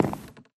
Sound / Minecraft / step / wood4.ogg
wood4.ogg